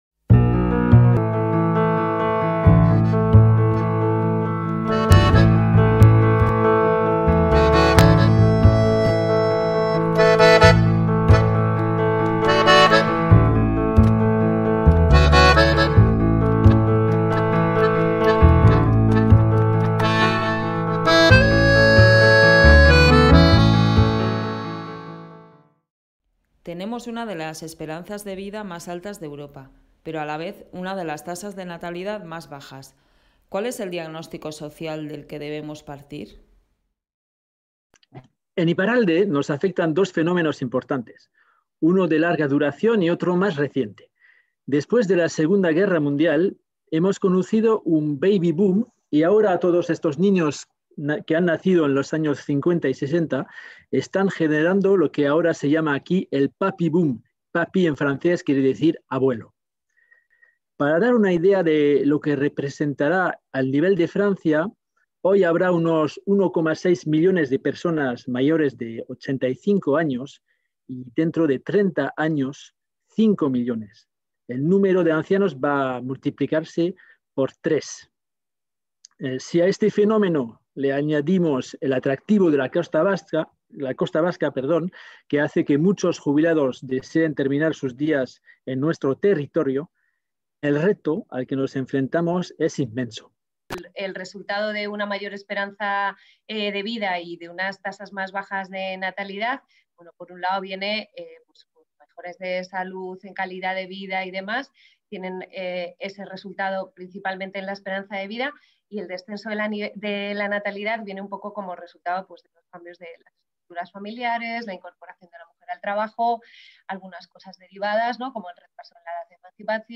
Con motivo del II Seminario Transfronterizo Envejecimiento y atención a la dependencia, la sección Solasaldiak invita a dialogar a dos especialistas.